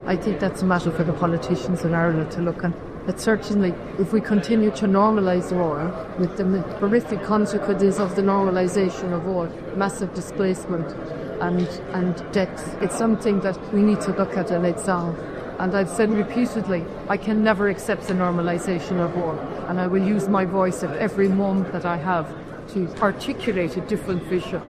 Earlier, she was asked for her view on the recent fuel protests: